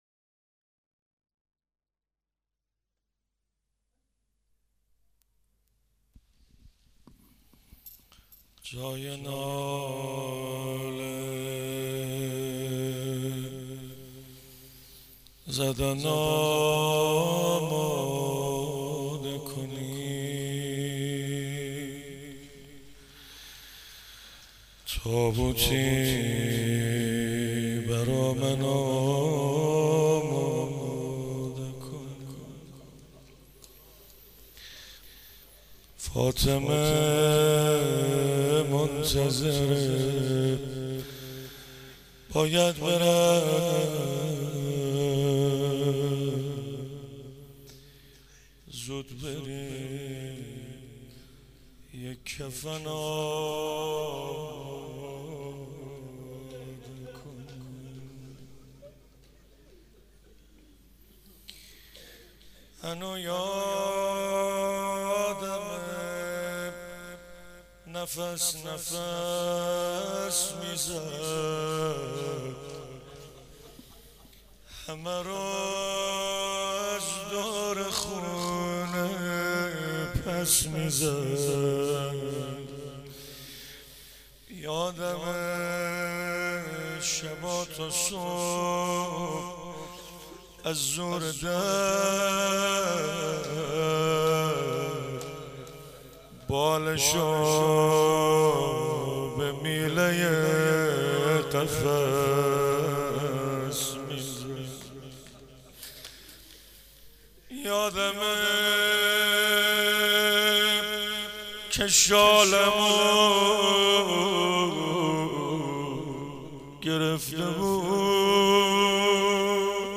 حسینیه حضرت زینب (سلام الله علیها)
مراسم شب 22 رمضان 96